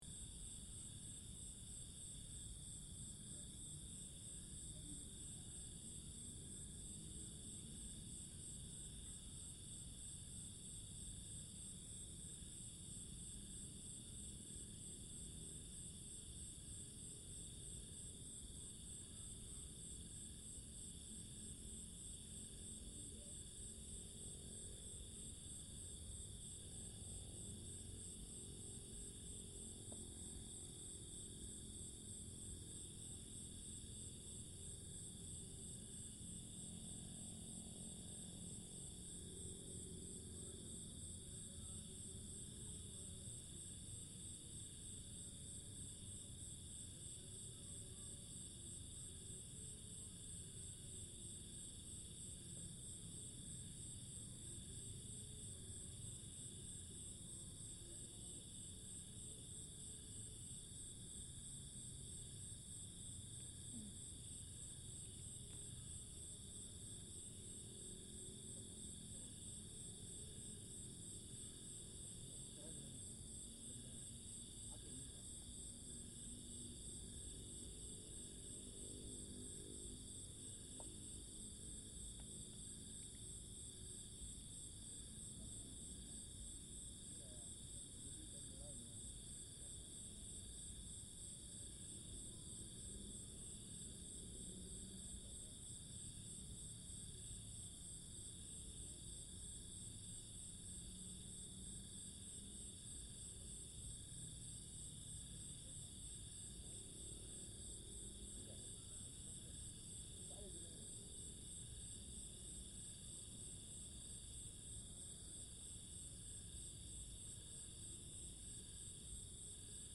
Angsapura cemetery soundscape
At that time I enjoyed my solitude by recording sound and drinking several bottles of beer, most the type sound can hear in this track.